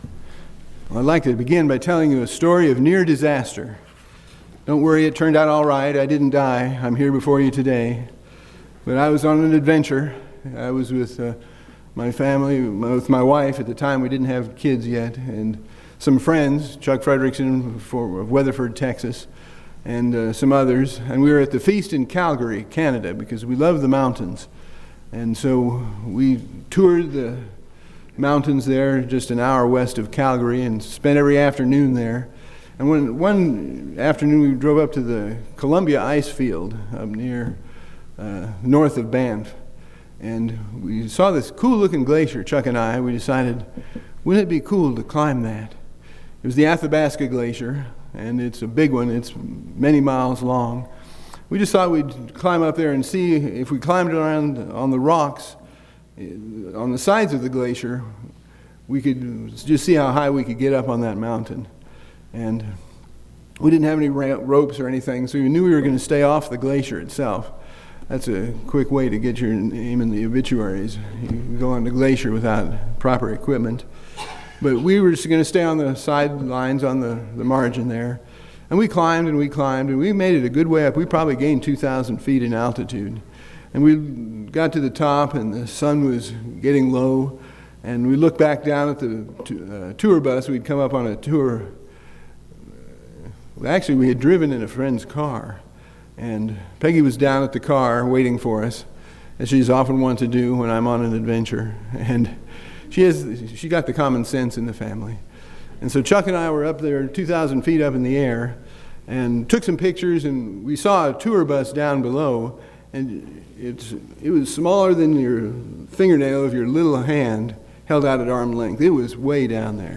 Using personal and biblical examples, this sermon encourages us all to ask God to guide our steps in matters great and small.
Given in Ft. Wayne, IN